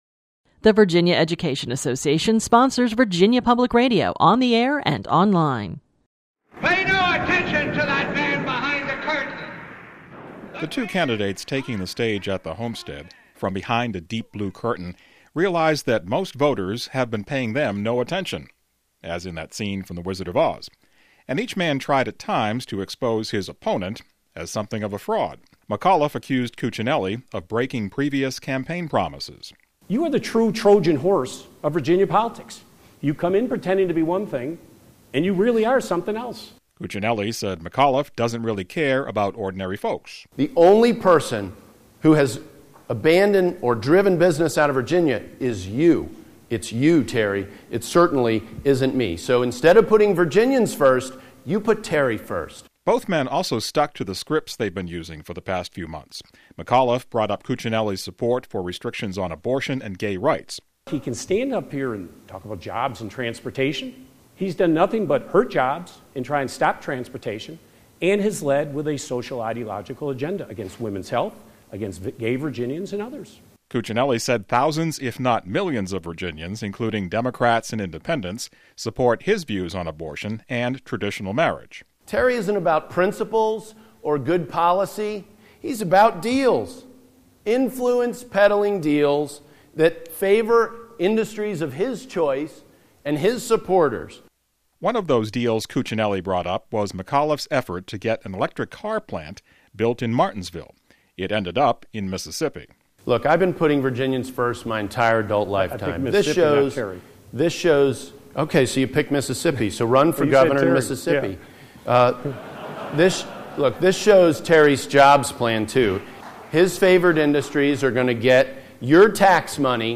But the two major party candidates for Virginia Governor are far apart on almost all the issues.  In their first debate, sponsored by the Virginia Bar Association, the two men set the tone for what is likely to be a tough, hard campaign.